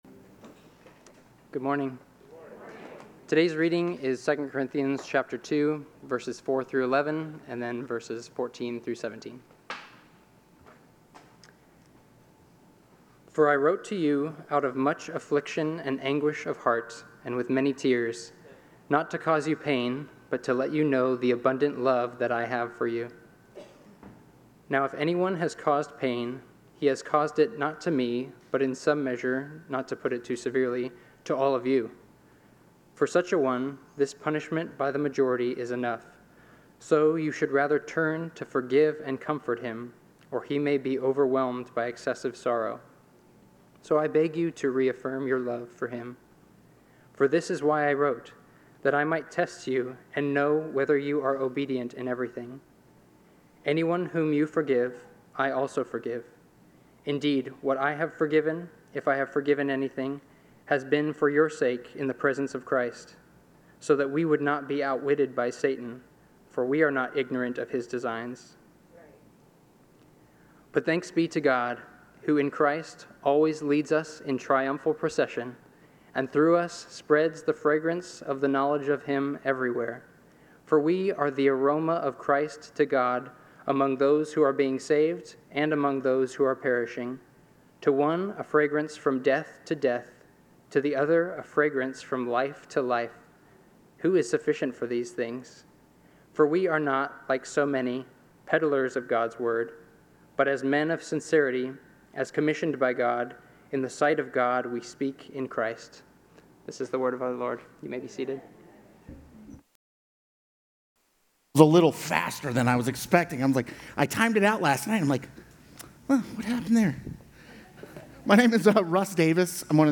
Sermon Notes: Forgiveness and Restoration